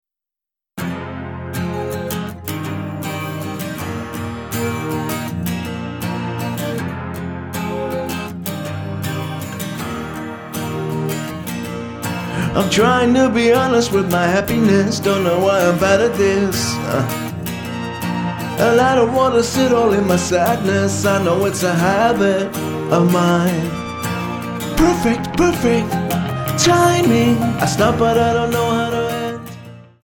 --> MP3 Demo abspielen...
Tonart:Fm Multifile (kein Sofortdownload.
Die besten Playbacks Instrumentals und Karaoke Versionen .